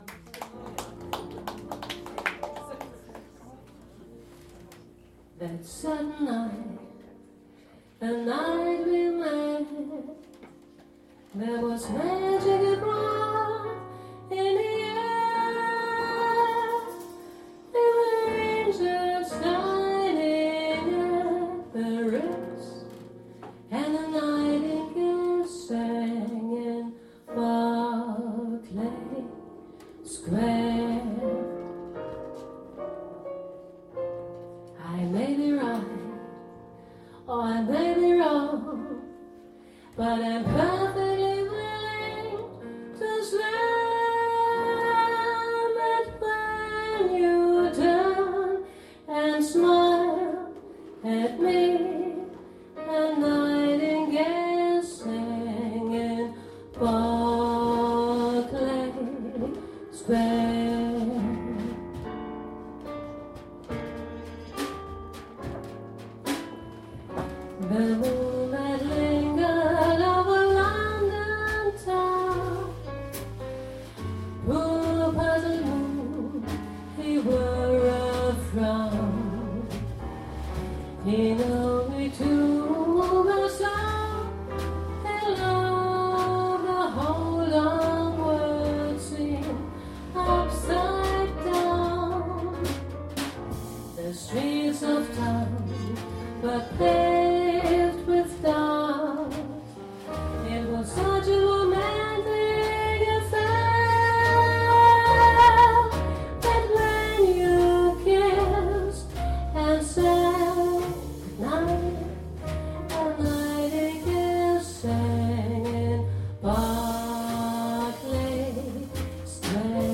Shrink&Jazz in Vögi's Chäller
Ein Konzert in einer einmaligen Lokalität.
trumpet